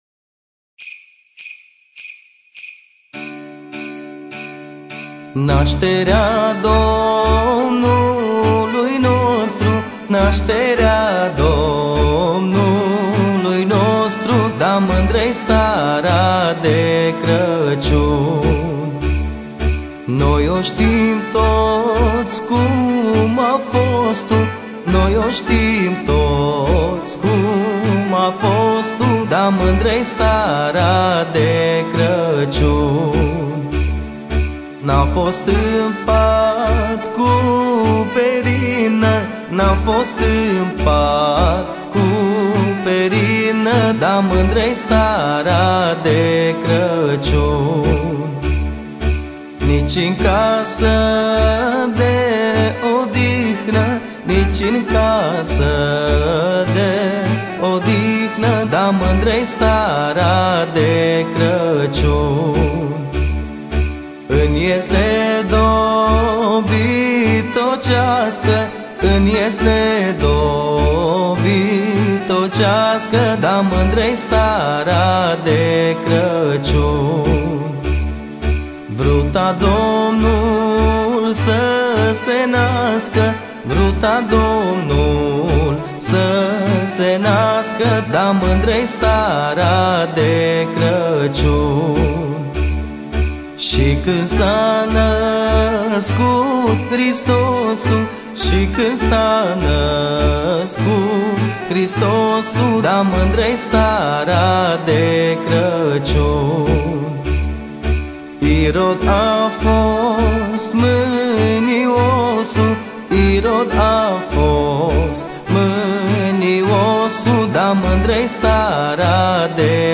Categoria: Colinde Craciun